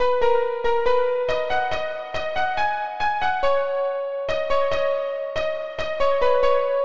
描述：听起来像。快乐，中国人。
Tag: 140 bpm Ambient Loops Piano Loops 1.15 MB wav Key : Unknown